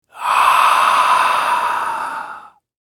Horror Scary Human Whisper Ghost 05 - Botão de Efeito Sonoro